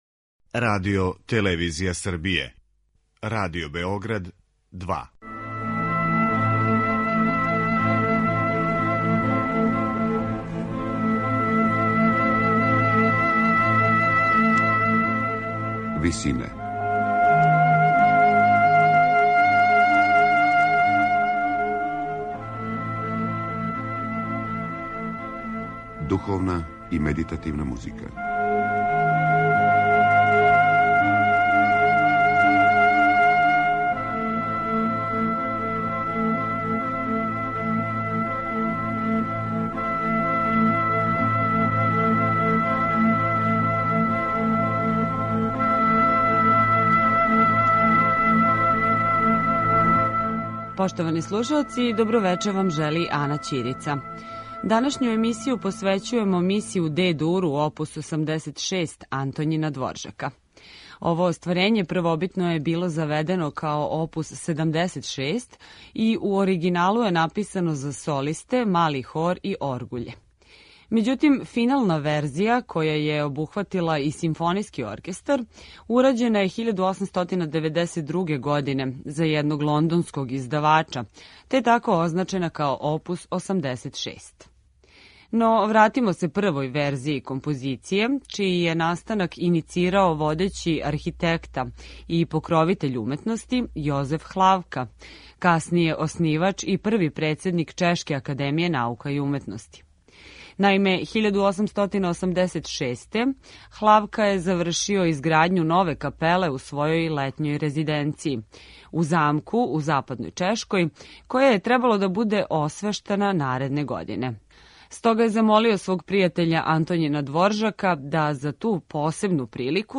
Миса у Де-дуру
Ово остварење првобитно је било заведено као опус 76 и у оригиналу је написано за солисте, мали хор и оргуље. Међутим, финална верзија, која је обухватила и симфонијски оркестар, урађена је 1892. године за једног лондонског издавача, те тако означена као опус 86.